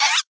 land_idle4.ogg